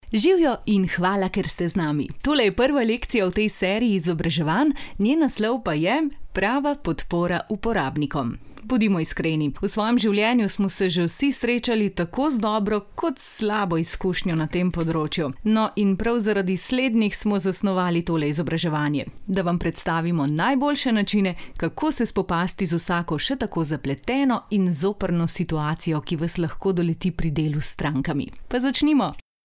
Kommerziell, Natürlich, Vielseitig, Zuverlässig, Warm
E-learning